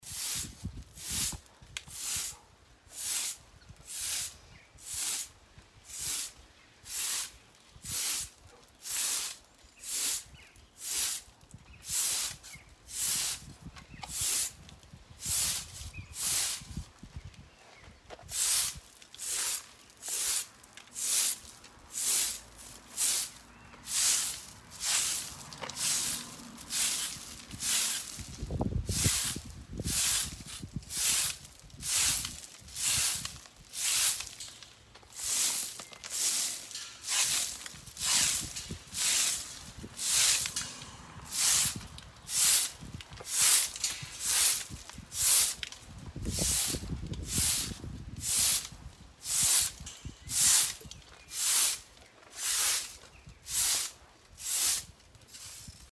Звуки метлы
Звук метлы дворника ранним утром